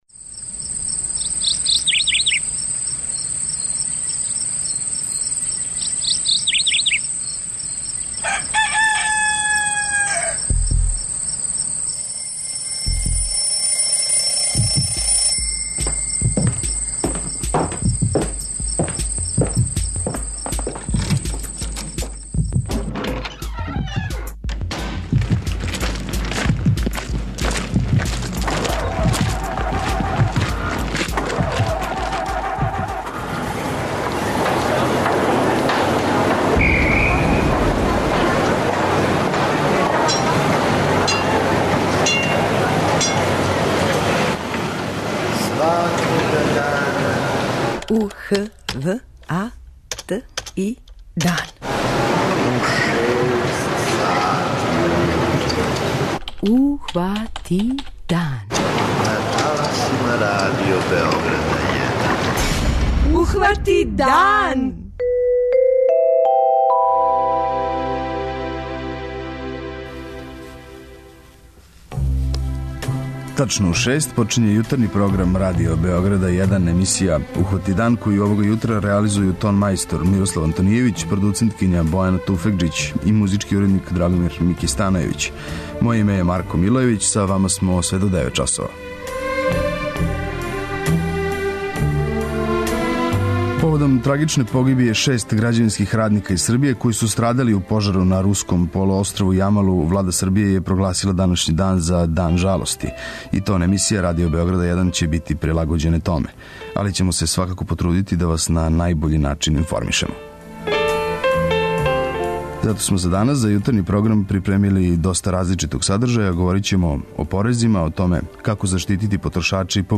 Због снега који је завејао пештерску висораван и јаког ветра који ствара сметове на том подручју уведена је ванредна ситуација. Разговарамо са председником те општине, Хазбом Мујовићем.